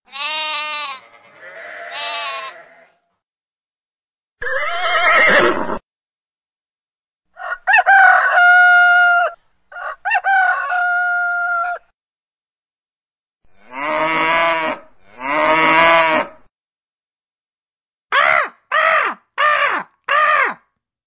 Joc d’escolta
Aquí us deixo el joc d’escolta, hi ha 5 sons diferents.
animals.mp3